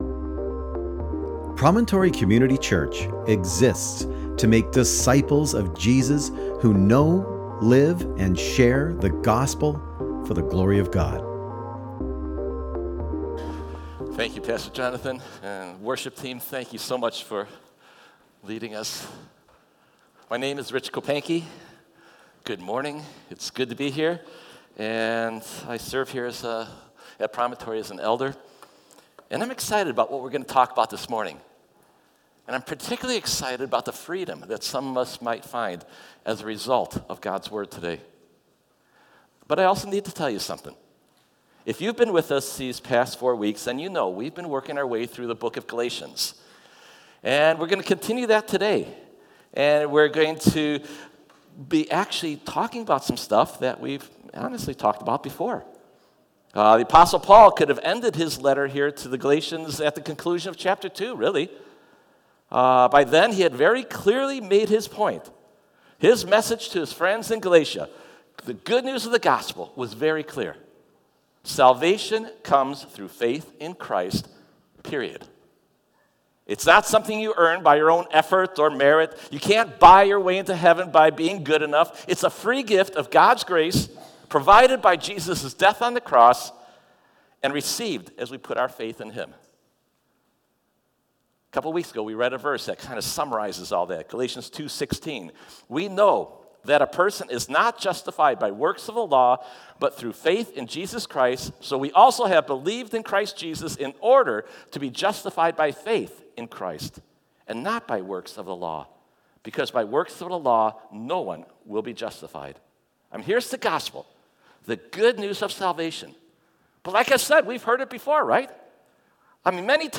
Study Notes Sermon